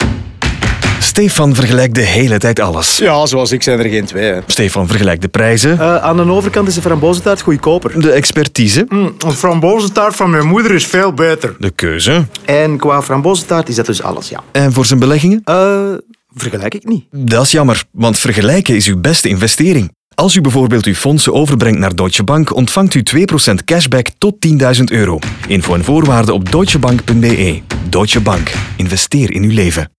En laten we de radiospot niet vergeten, Hierin komt Stéphane aan het woord, een man die de hele tijd alles vergelijkt.
DeutscheBank-DeVergelijker-Radio-NL-30s-Algemeen-051217.mp3